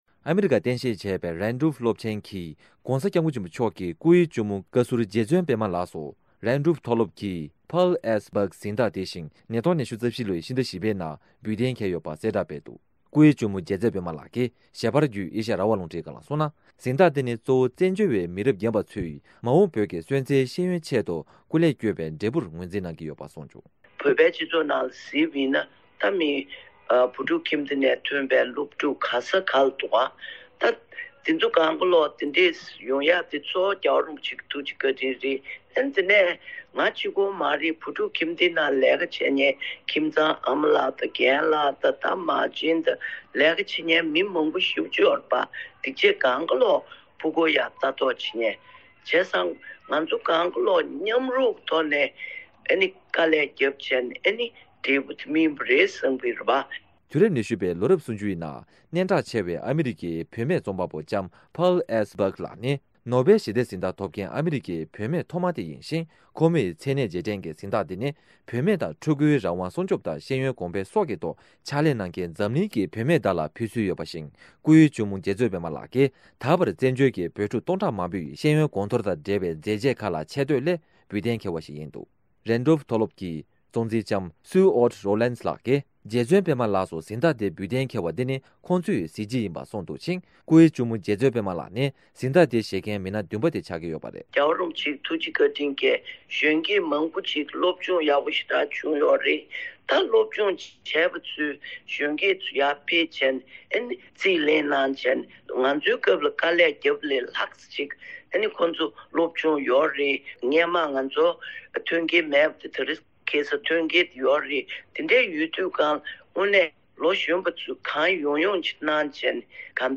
༸སྐུའི་གཅུང་མོ་རྗེ་བཙུན་པདྨ་ལགས་ཀྱིས། ཞལ་པར་བརྒྱུད་ཨེ་ཤེ་ཡ་རང་དབང་རླུང་འཕྲིན་ཁང་ལ་གསུངས་ན། གཟེངས་རྟགས་དེ་ནི་གཙོ་བོ་བཙན་བྱོལ་བའི་མི་རབས་རྒན་པ་ཚོས་མ་འོངས་བོད་ཀྱི་སོན་རྩ་ཚོའི་ཤེས་ཡོན་ཆེད་དུ་སྐུ་ལས་བསྐྱོན་པའི་འབྲས་བུར་ངོས་འཛིན་གནང་གི་ཡོད་པ་གསུངས་བྱུང་།